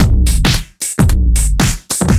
OTG_DuoSwingMixA_110b.wav